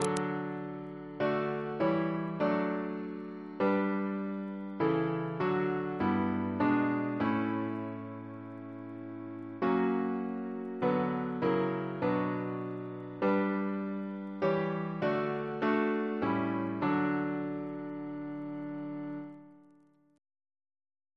Double chant in D Composer: Sir George Elvey (1816-1893), Organist of St. George's Windsor; Stephen's brother Reference psalters: ACB: 165; ACP: 60; CWP: 127; PP/SNCB: 47; RSCM: 47